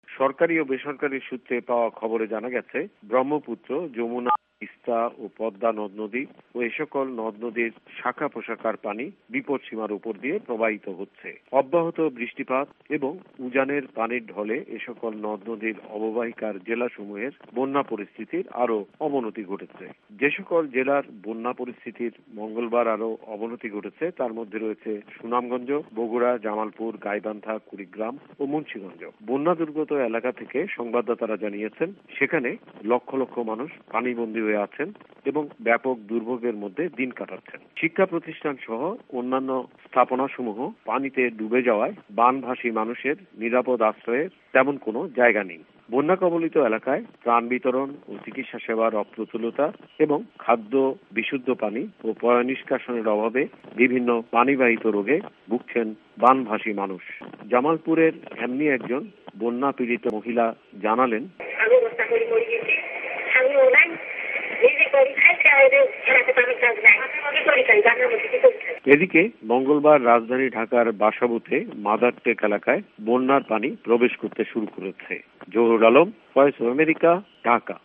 জামায়াতের নেতাকর্মীর বিরুদ্ধে চার্জ গঠন এবং বন্যা পরিস্থিতি বিষয়ে ঢাকা সংবাদদাতাদের রিপোর্ট